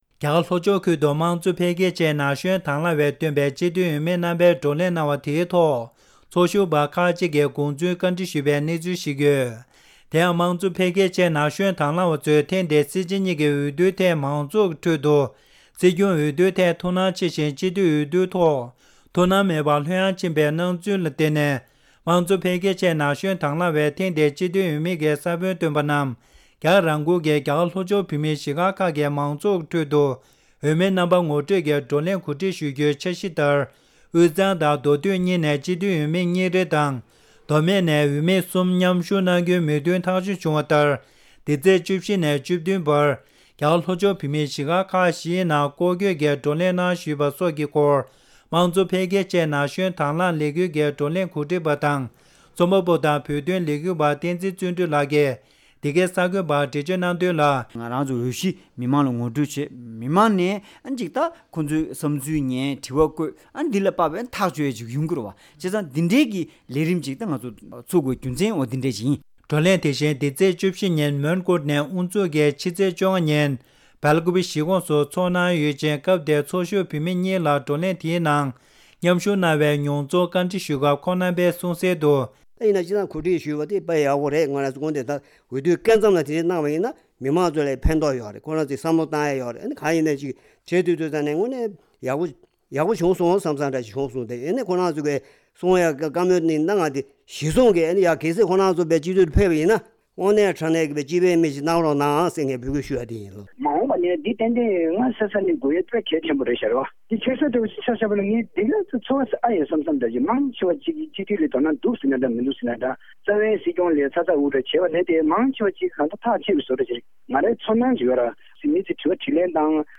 གསར་འགོད་པ